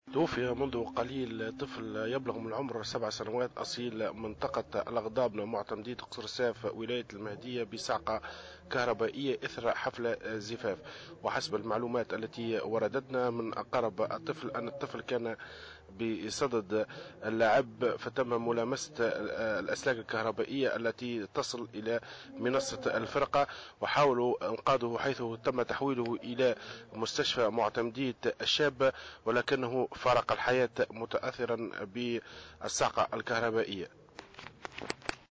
مراسلنا في الجهة